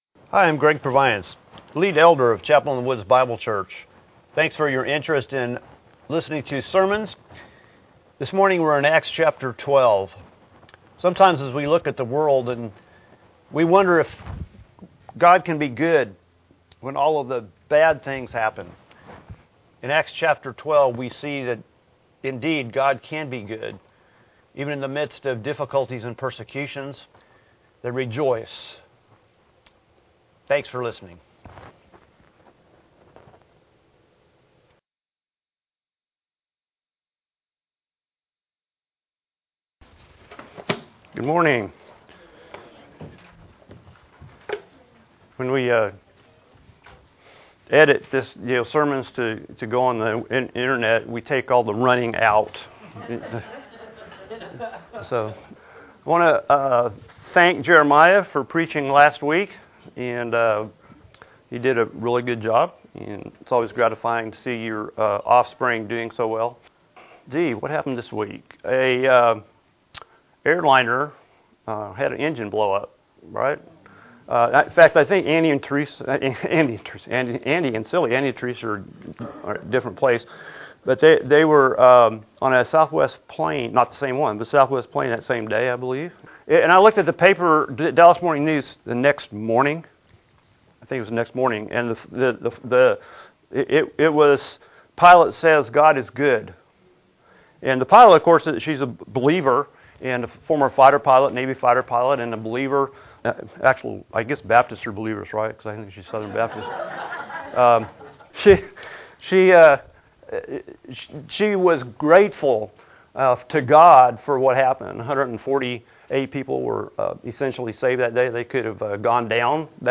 Apr 24, 2018 Acts 12 Grief and joy, God is good MP3 SUBSCRIBE on iTunes(Podcast) Notes Discussion Sermons in this Series Herod the king has killed James, the brother of John and has put Peter in prison. God supernaturally releases Peter with hilarity and joy for the church.